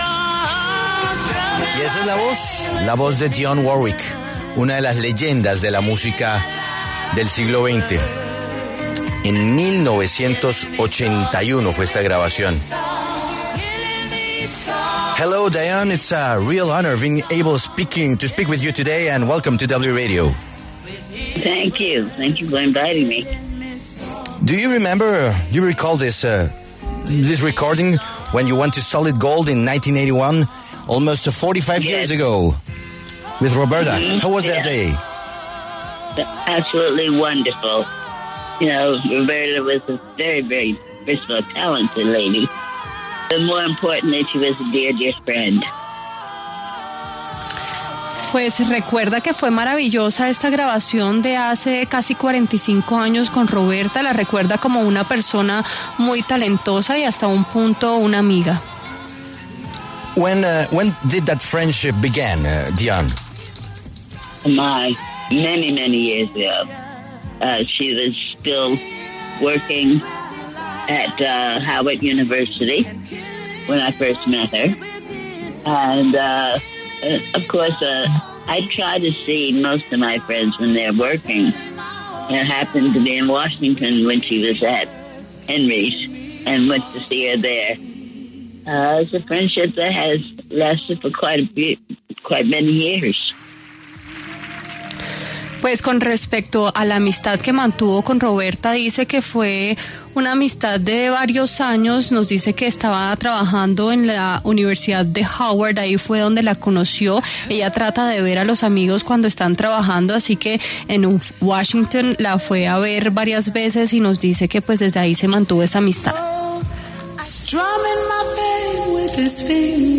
La legendaria artista, Dionne Warwick, conversó con La W sobre sus éxitos en la música y cómo fue trabajar de la mano de Roberta Flack.